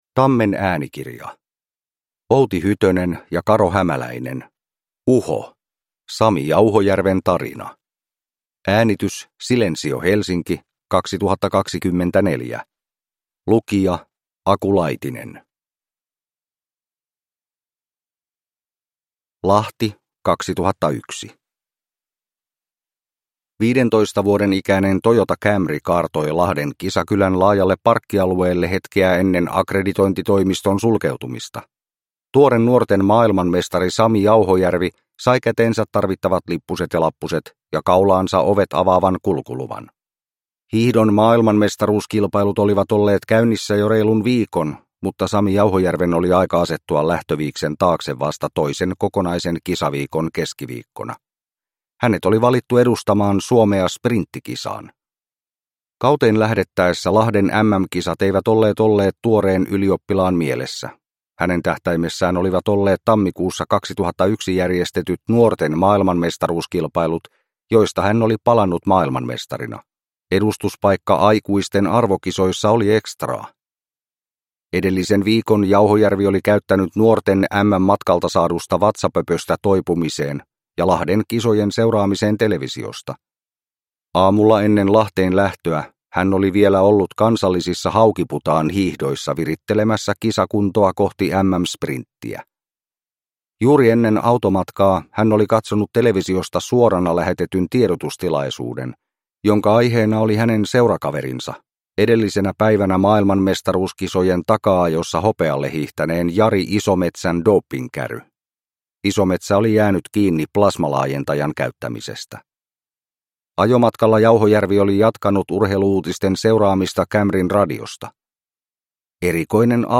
Uho - Sami Jauhojärven tarina – Ljudbok